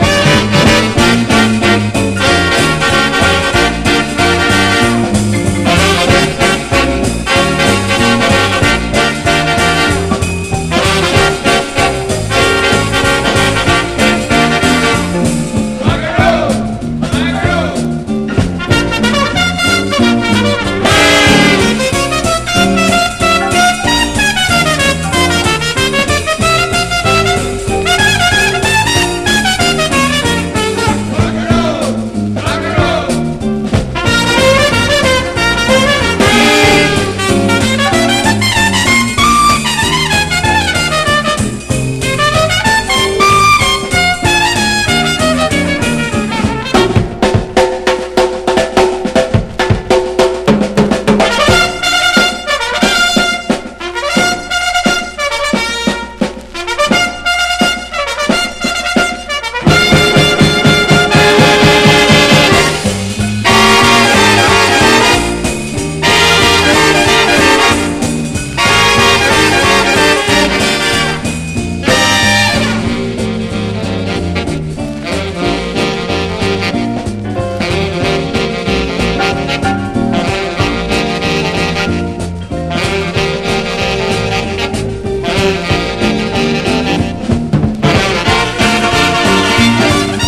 ネタとしても楽曲としても名曲揃いのメロウ・アルバム！